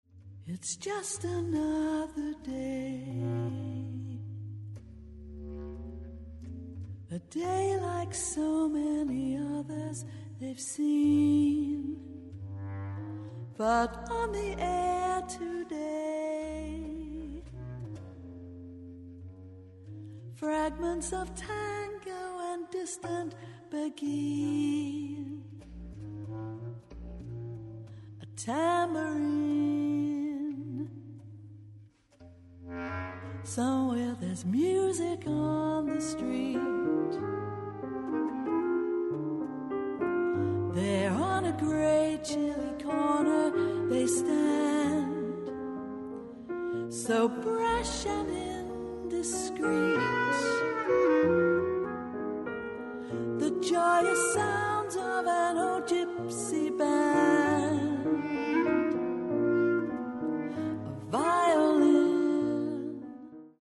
Recorded December 2012, Auditorio Radiotelevisione, Lugano
Piano
Sop. Sax, Bs Clt